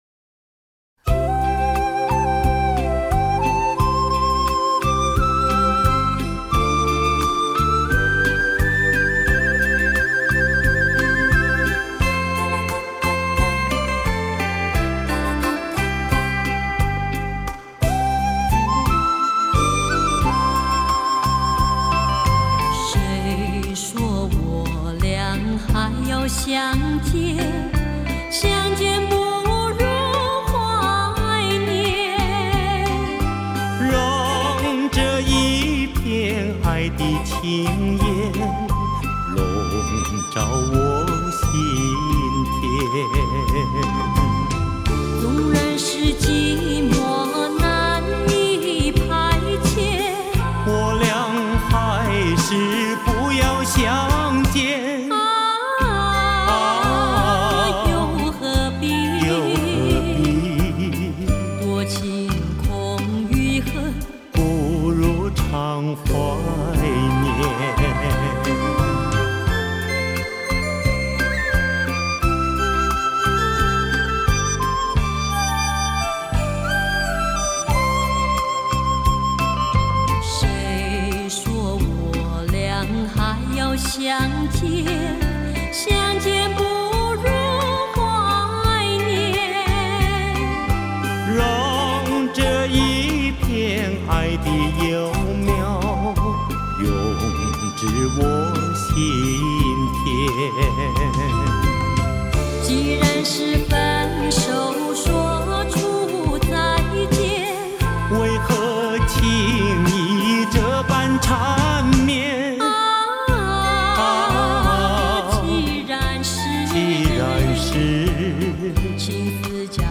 [12/4/2009]若问相思甚了期 除非相见时：《长相思》（古音埙） 激动社区，陪你一起慢慢变老！